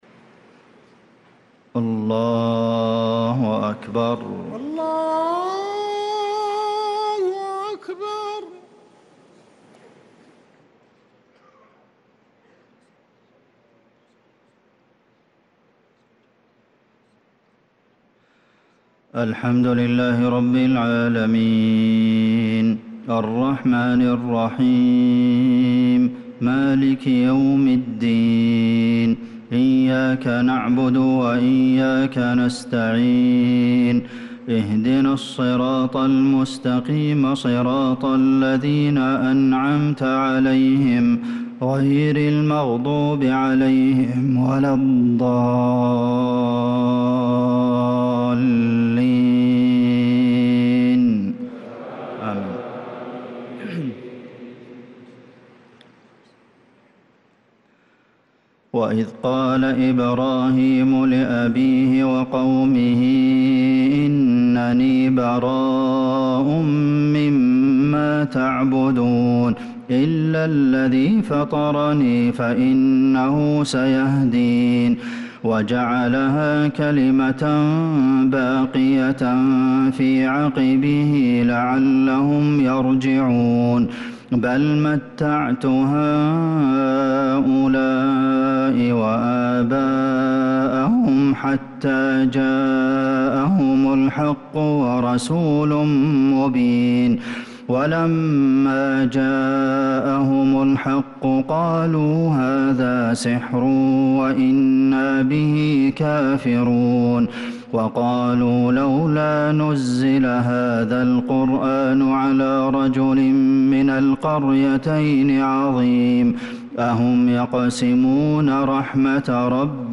صلاة العشاء للقارئ عبدالمحسن القاسم 7 ربيع الأول 1446 هـ
تِلَاوَات الْحَرَمَيْن .